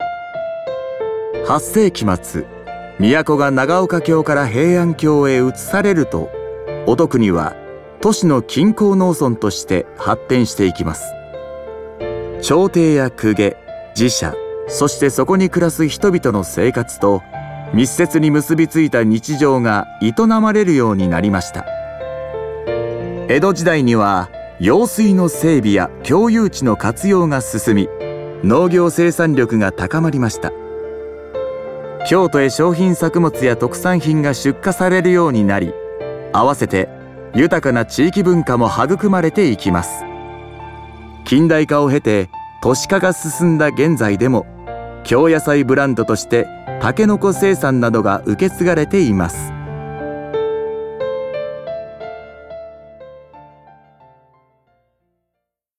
音声ガイド